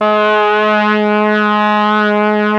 RED.BRASS 14.wav